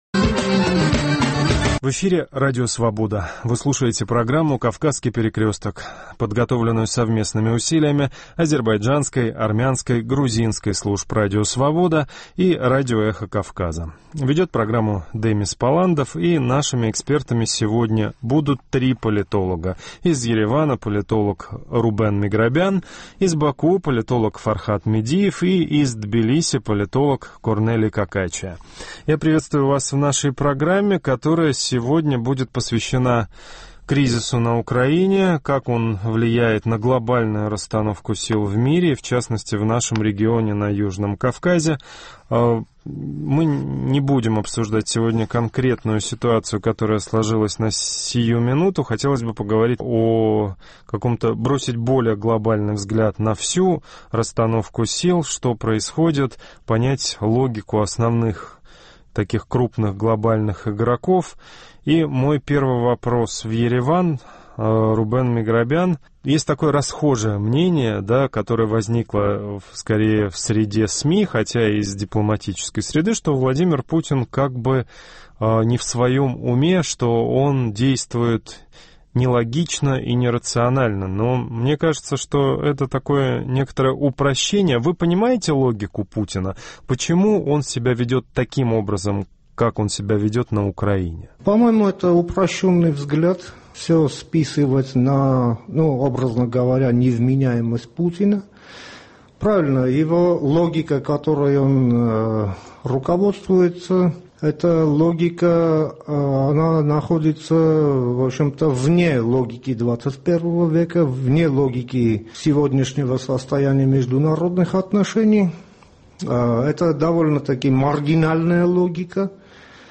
რადიო თავისუფლების ქართული, სომხური და აზერბაიჯანული რედაქციების ერთობლივ პროგრამაში დღეს საუბარი გვაქვს უკრაინის კრიზისის გავლენაზე მსოფლიო პოლიტიკასა და, კერძოდ, სამხრეთ კავკასიაზე. გადაცემაში მონაწილეობენ პოლიტოლოგები